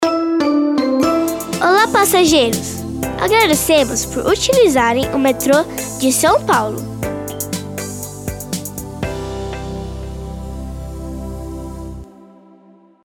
OUÇA: Transportes de São Paulo recebem avisos sonoros gravados por crianças
Já no Metrô, entre 11 e 31 de outubro, os avisos sonoros educativos e de gentileza são feitos nas vozes de nove crianças.